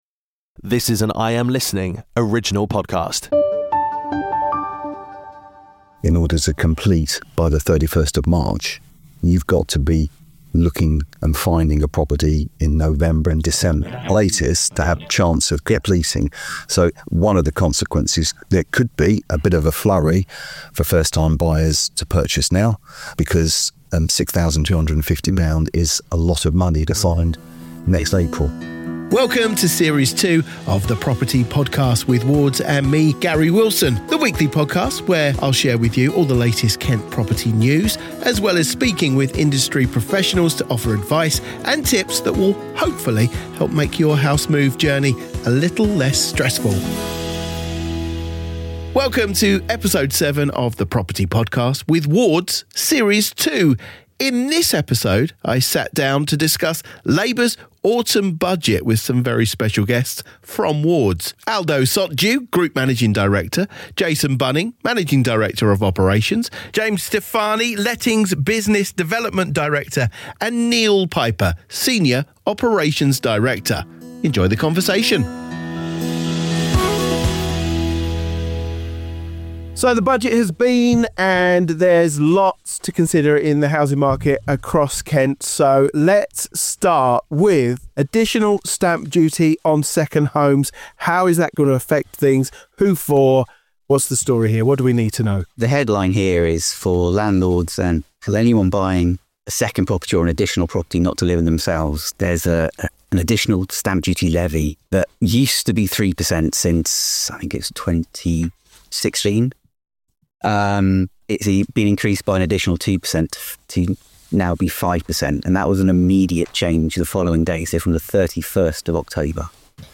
In this episode, I sit down with some special guests from Wards to delve into Labour's Autumn Budget and its implications on the property market in Kent.